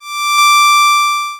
BadTransmission2.wav